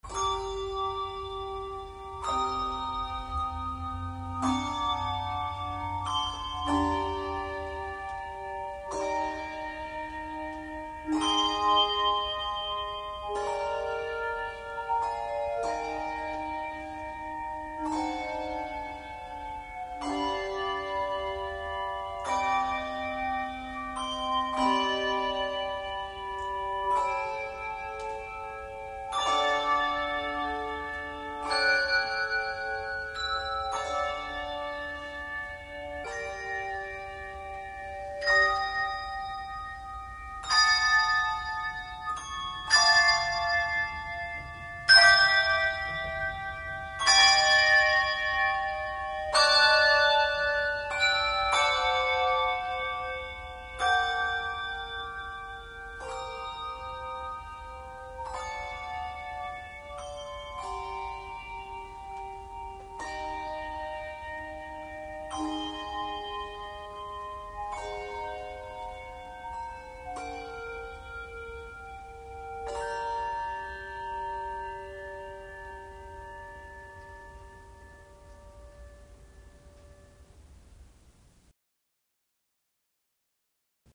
Handbell Quartet
No. Octaves 3 Octaves